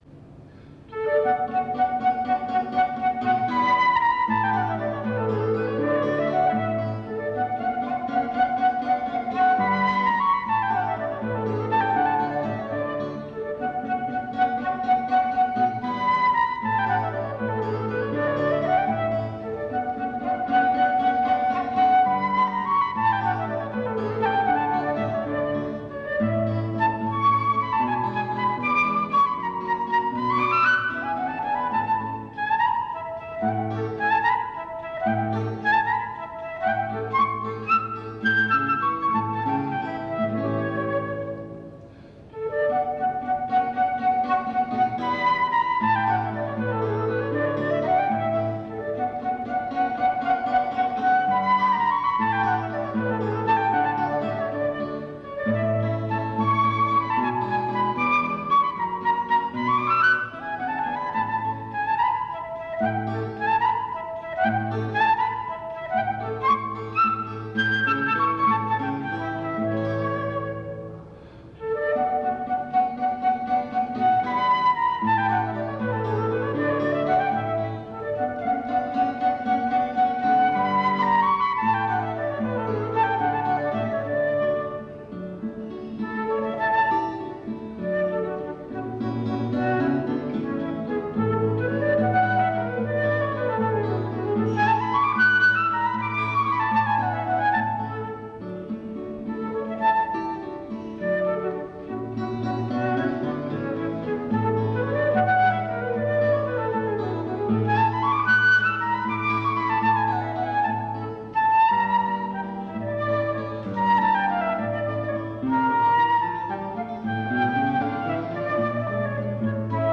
Duo flauto e chitarra
flauto
chitarra
Circolo Eridano, Torino 2 Aprile 1993